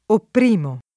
oppr&mo] — pass. rem. oppressi [